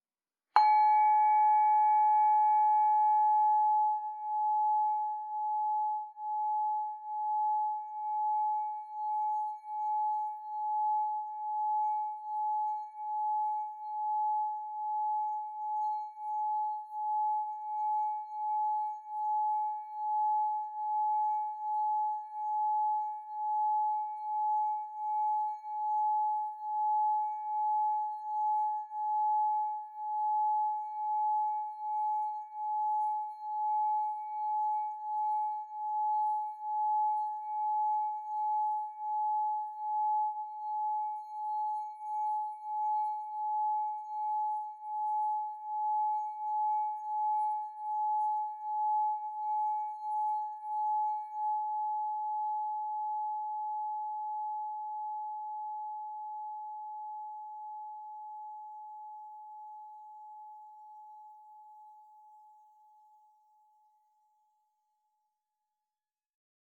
Meinl Sonic Energy 3.75" Essence Solfeggio Crystal Singing Bowl La 852 Hz, Mango Mojito (ESOLCSB852)